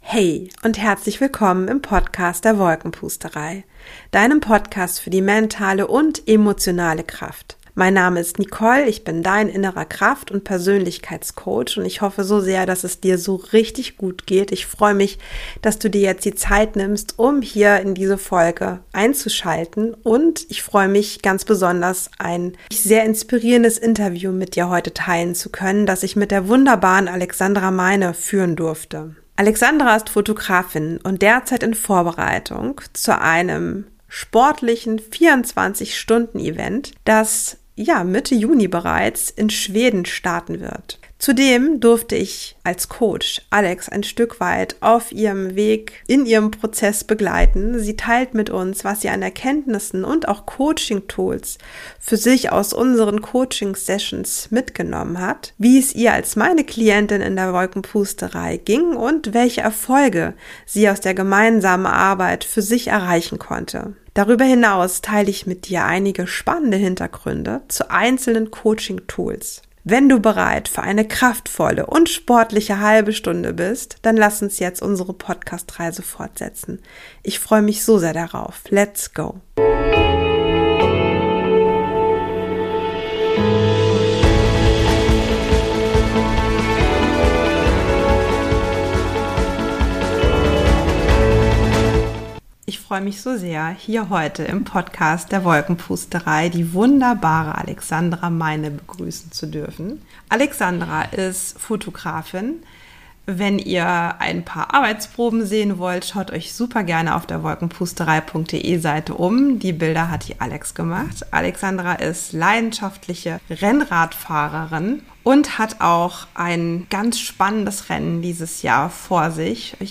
#42 Über Vertrauen und den Mut Neues zu wagen - Interview